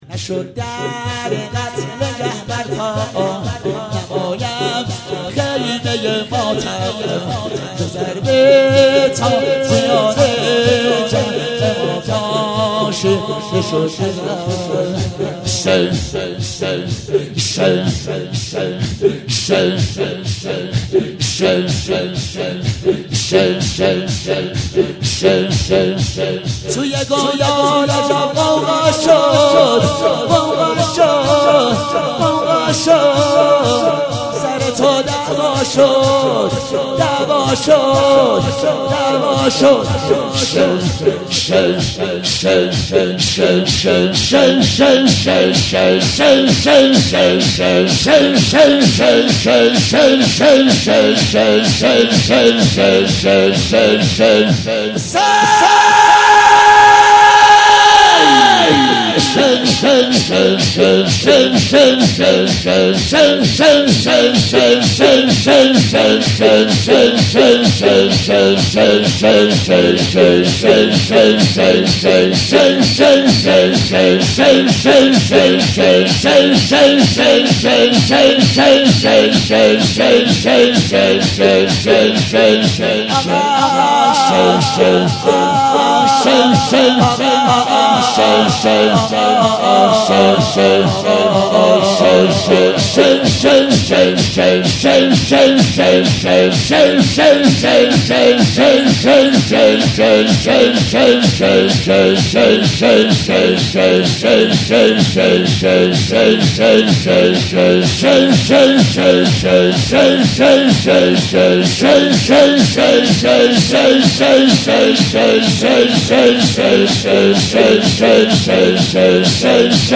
هفتگی 29 فروردین98 - شور - نشد در قتله گه بر پا
هفتگی بهار98